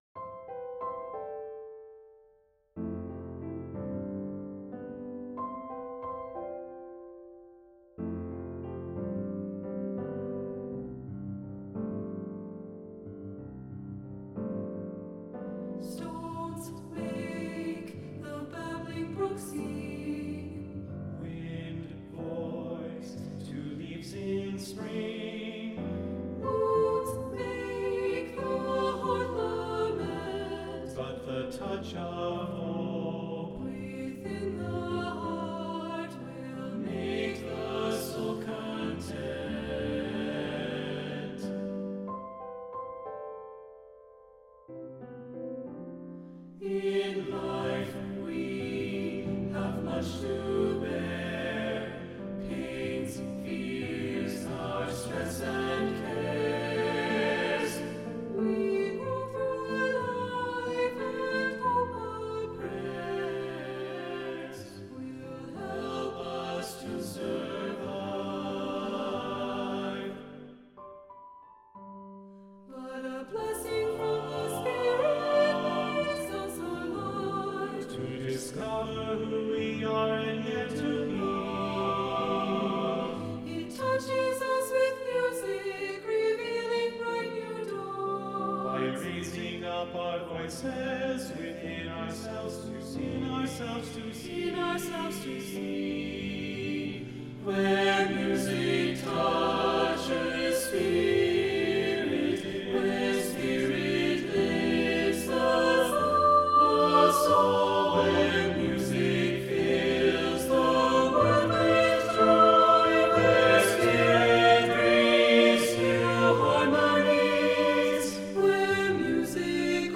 Voicing: SATB
Instrumentation: Piano